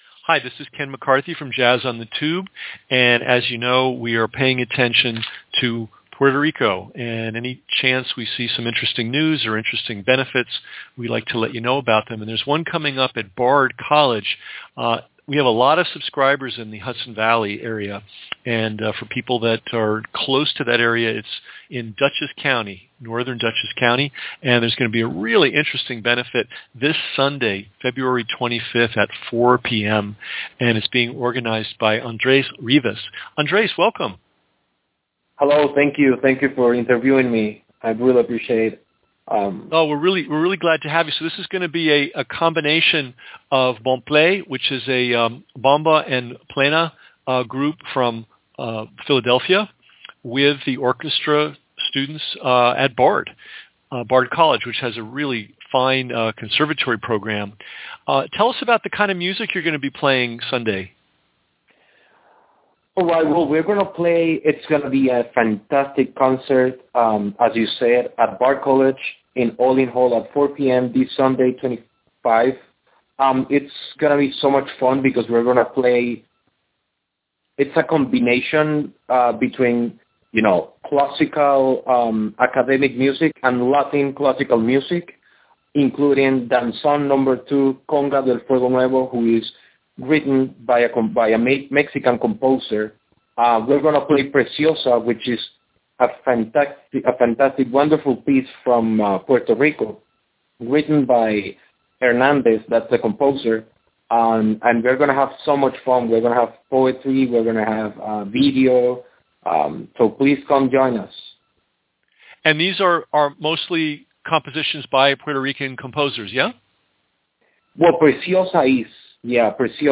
Jazz on the Tube Interview